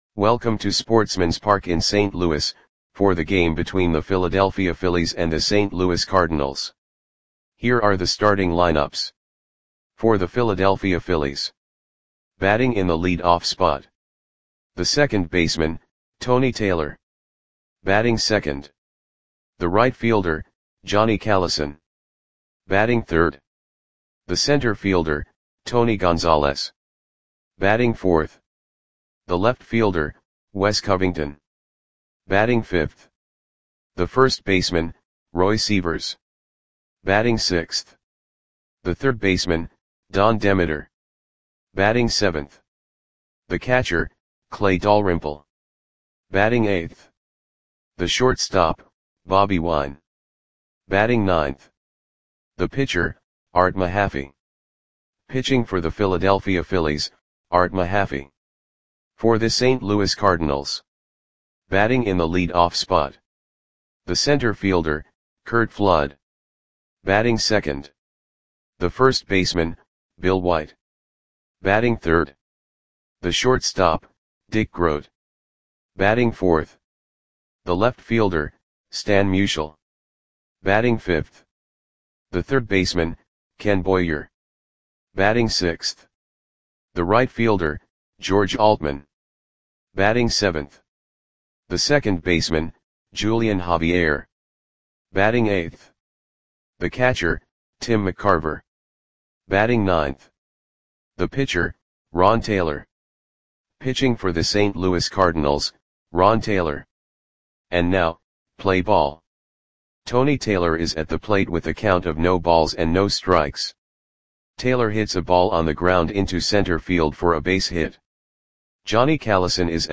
Audio Play-by-Play for St. Louis Cardinals on June 12, 1963
Click the button below to listen to the audio play-by-play.